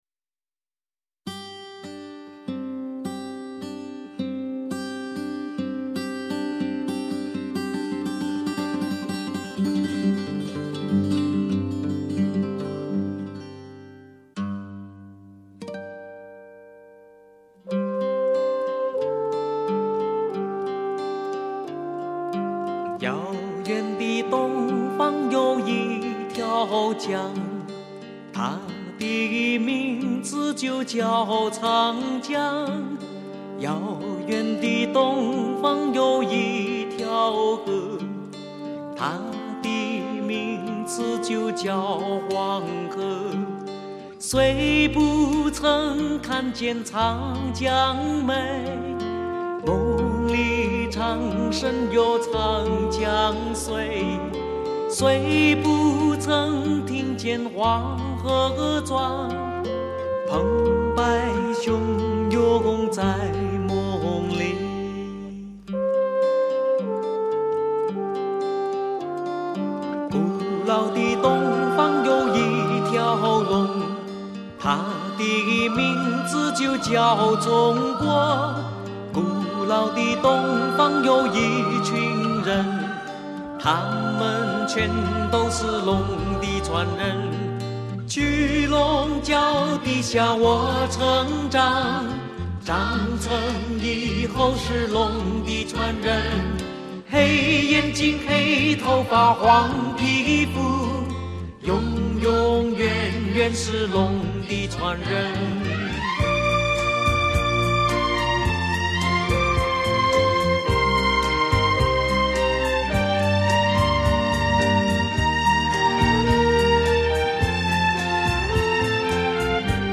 虽然普通话带着浓重的港味
整曲大气磅礴、震撼人心